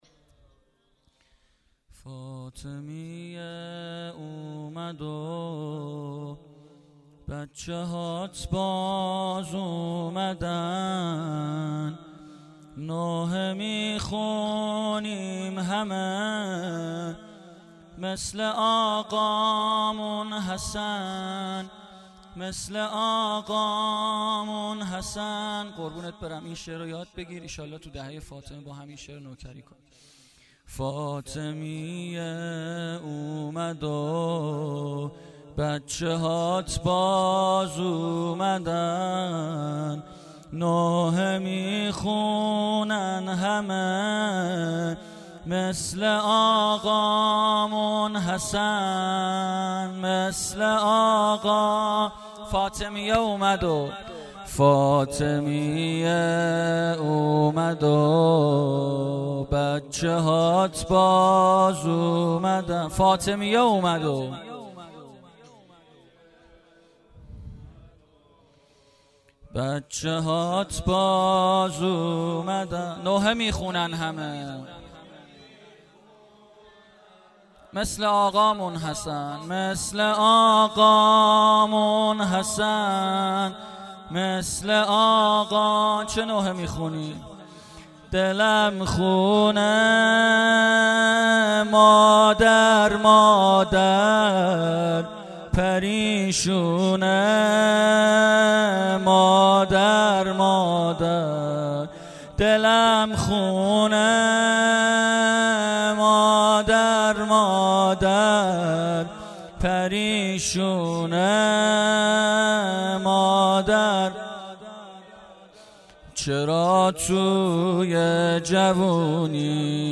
فایل کامل شب اول فاطمیه 96